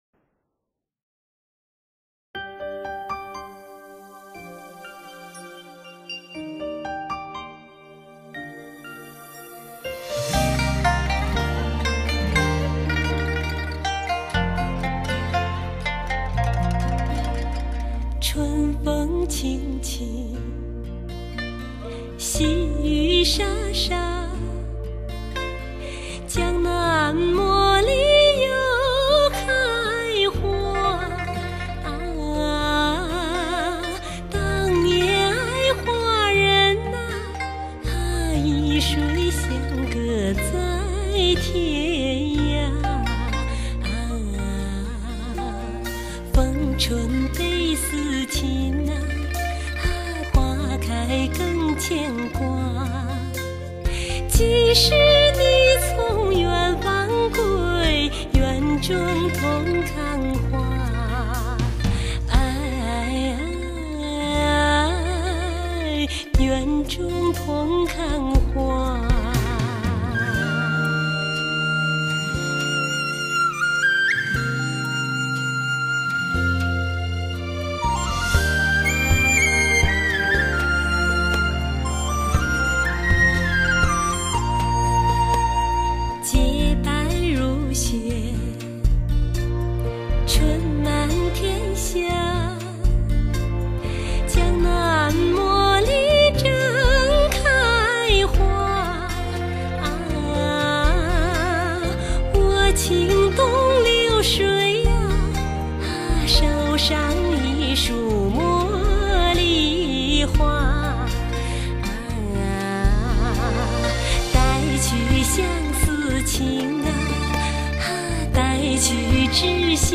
[26/6/2010]新新民歌:茉莉花开